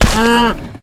Index of /HCU_SURVIVAL/Launcher/resourcepacks/HunterZ_G4/assets/minecraft/sounds/mob/cow
hurt2.ogg